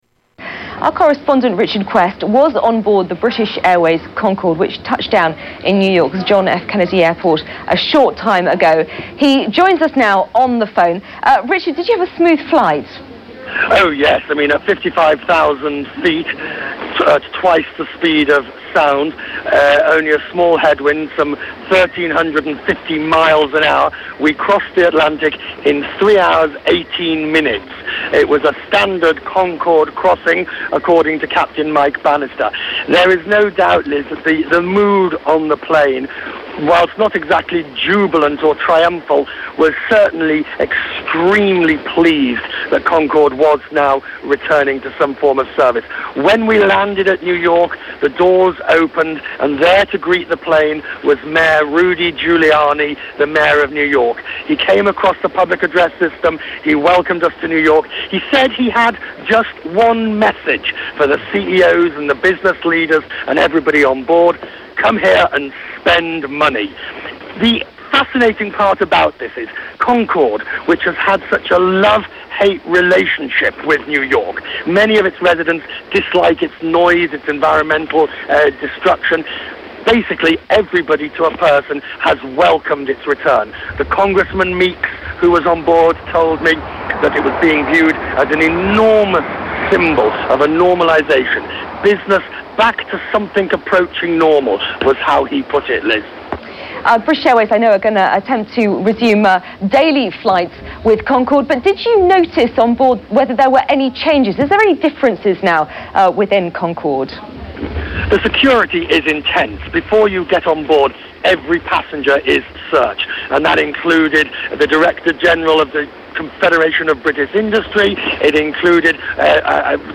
Tags: Media Richard Quest News Reporter Business Traveller Richard Quest Audio clips